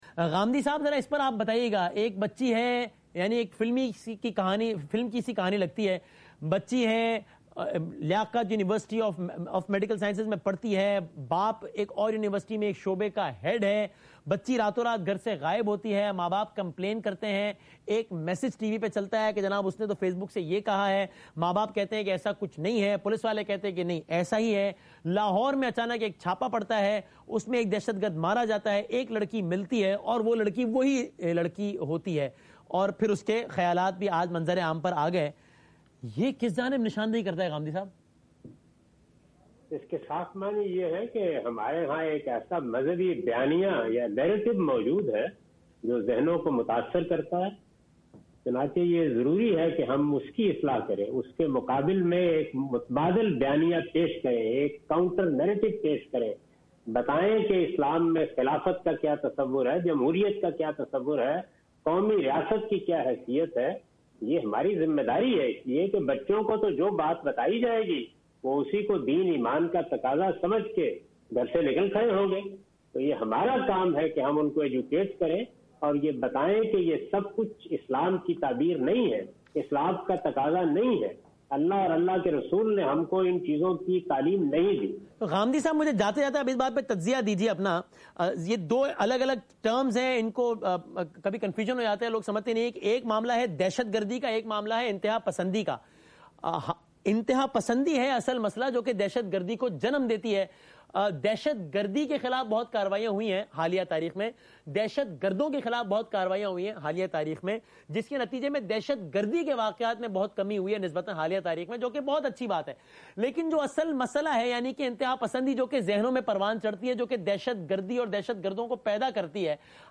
TV Programs